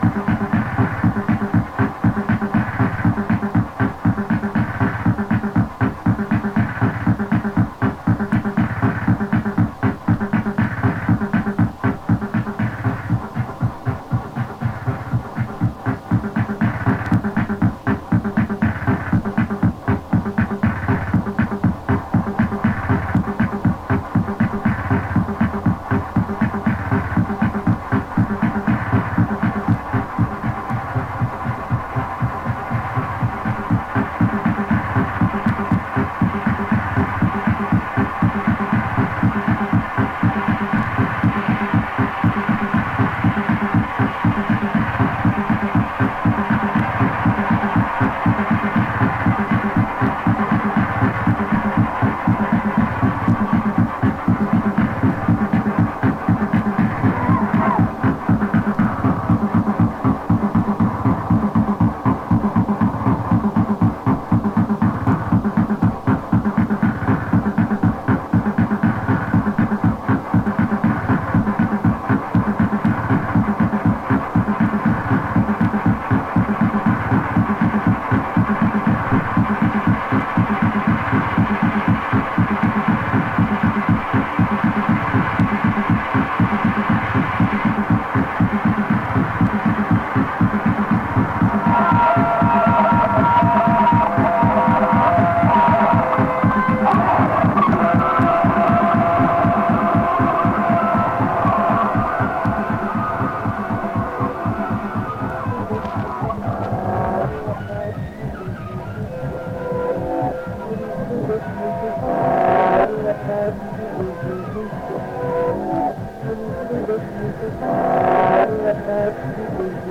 Música bélica.